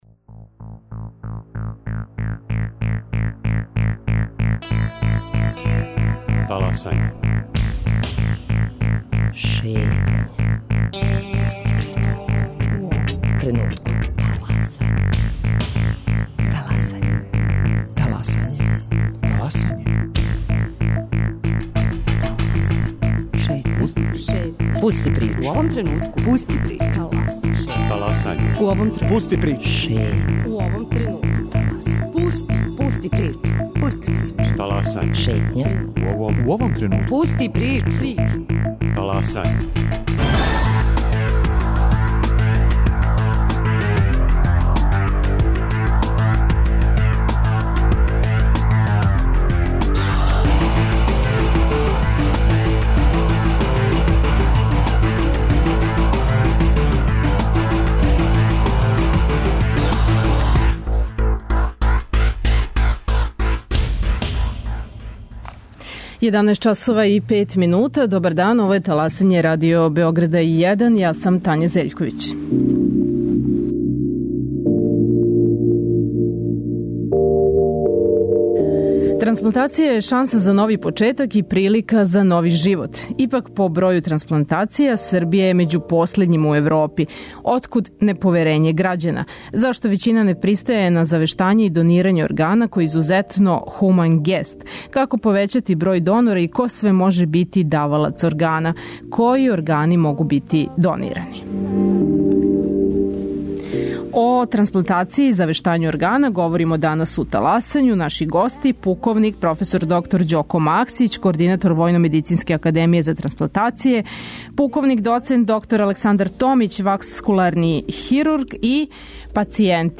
Чућемо и искуства оних који су донирали своје органе.